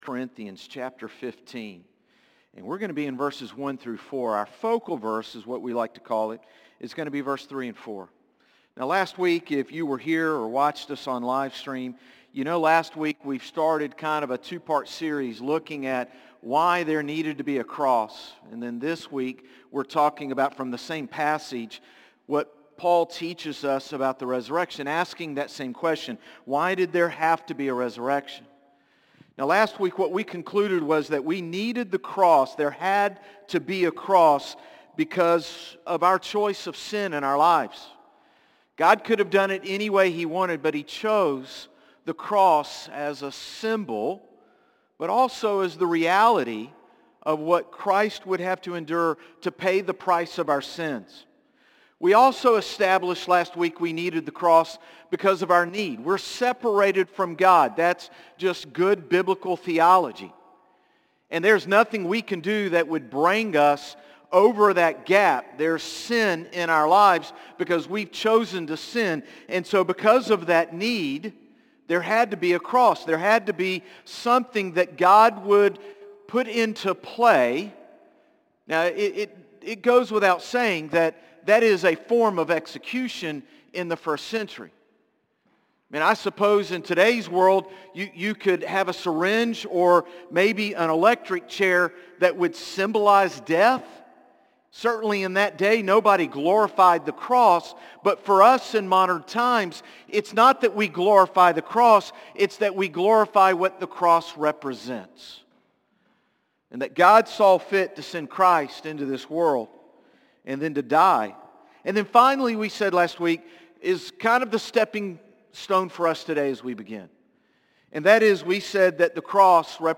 Sermons - Concord Baptist Church
Morning-Service-4-20-25.mp3